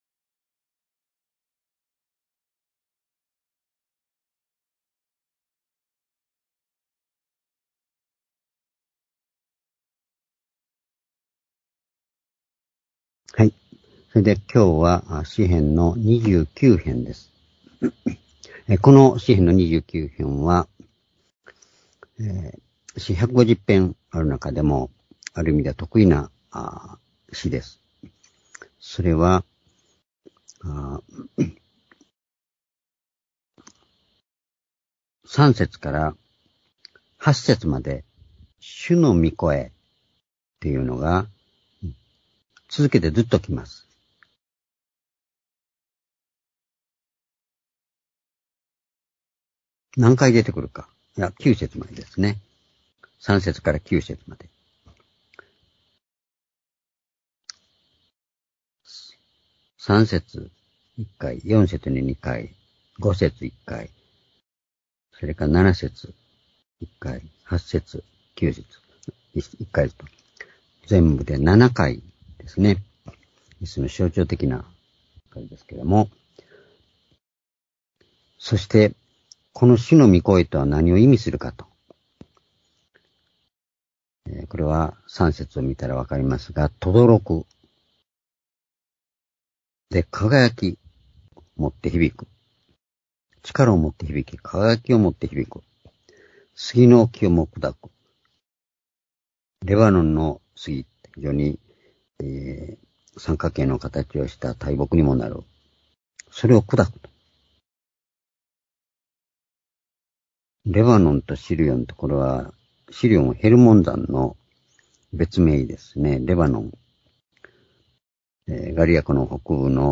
（主日・夕拝）礼拝日時 2022年8月2日（夕拝） 聖書講話箇所 「主のみ声の力」 詩篇29編1節～11節 ※視聴できない場合は をクリックしてください。